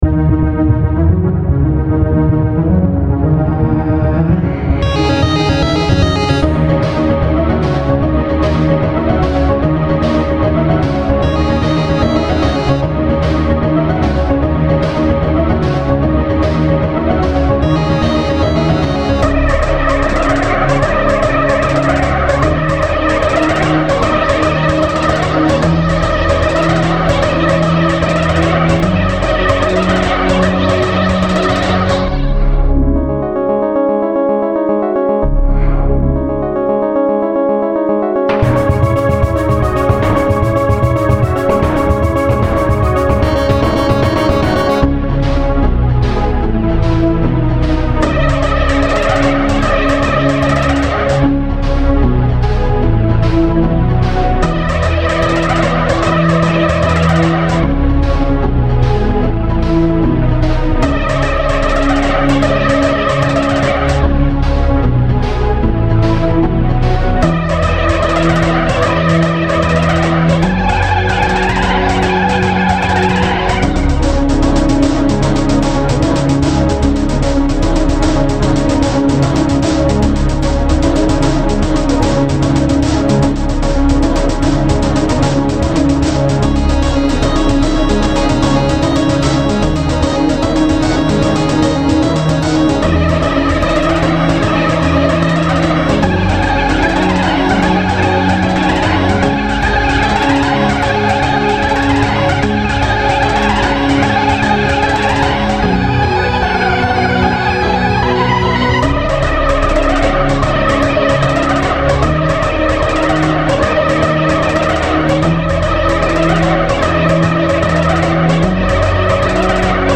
BPM75-150
Audio QualityPerfect (Low Quality)